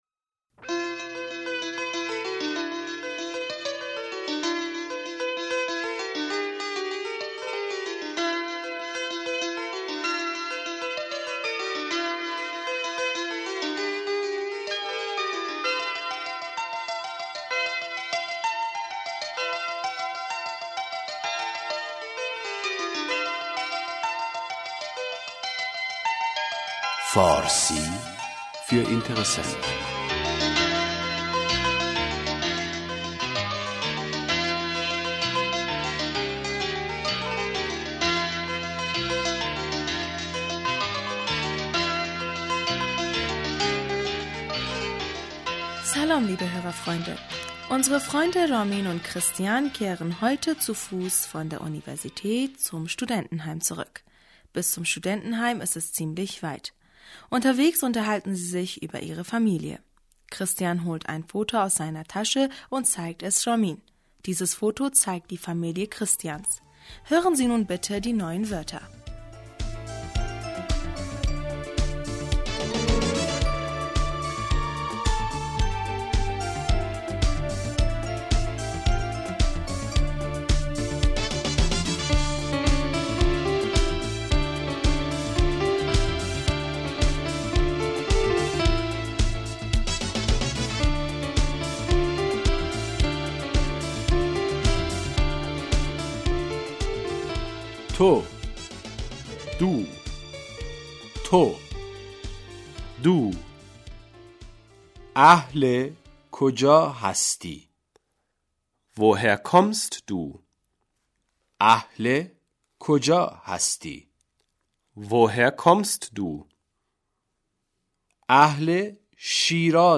Hören Sie jetzt das heutige Gespräche zwischen Christian und Raamin Sir-seda-Straßenlärm, Verkehr Zweimal CHRISTIAN: Raamin, woher komst du?